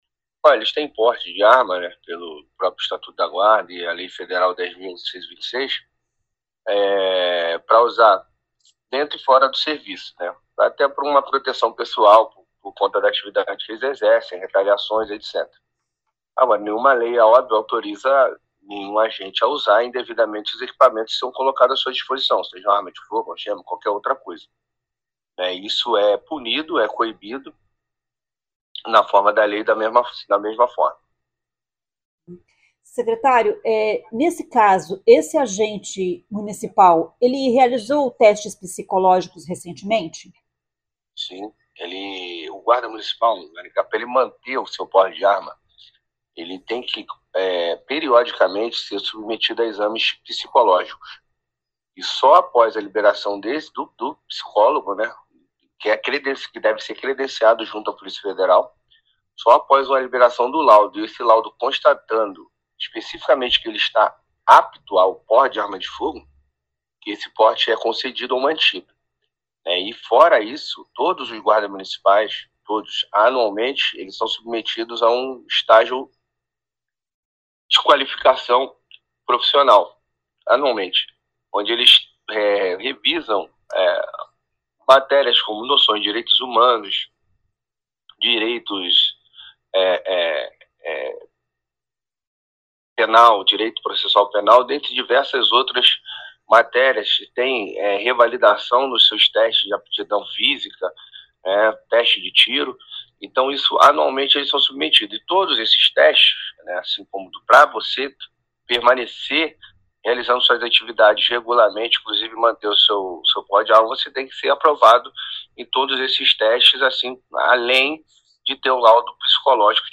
O secretário de Segurança de Maringá, delegado Luiz Alves, se pronunciou sobre o crime cometido neste fim de semana por um guarda civil municipal de Maringá com a arma da corporação.